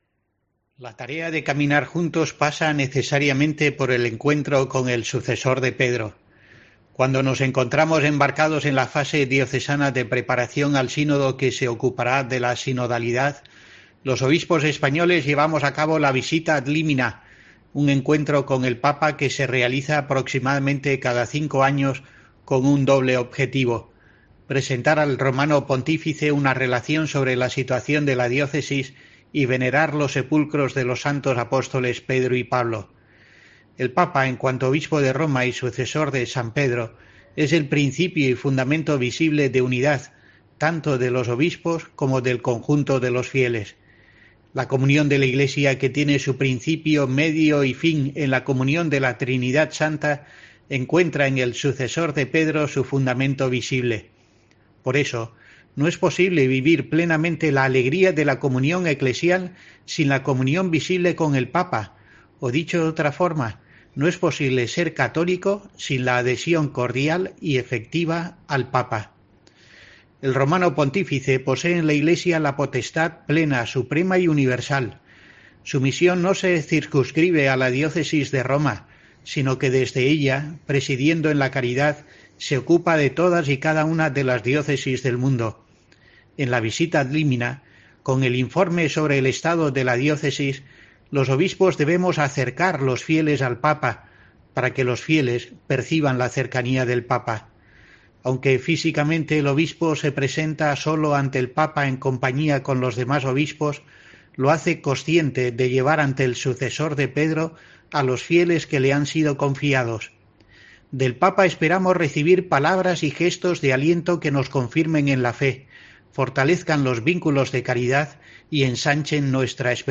El obispo de Asidonia-Jerez deja su mensaje semanal mientras, con la maleta hecha, se prepara para aterrizar el domingo en Roma e iniciar la Visita Ad Límina Apostolorum